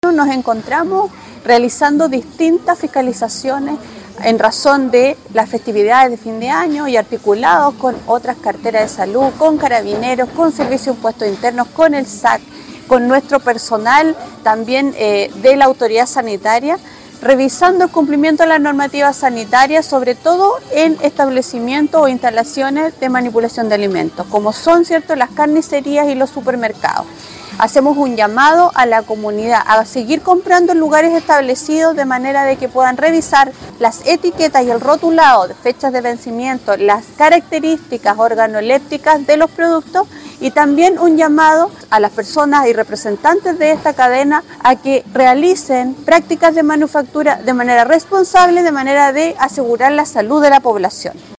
La seremi de Salud, Karin Solís, informó que, en el marco de las festividades de fin de año, se están llevando a cabo fiscalizaciones para garantizar el cumplimiento de normativas sanitarias en establecimientos como carnicerías y supermercados. Además, hizo un llamado a la comunidad a comprar en locales establecidos, verificando etiquetas, fechas de vencimiento y condiciones de los productos, e instó a los comerciantes a mantener prácticas responsables de manufactura para proteger la salud de la población.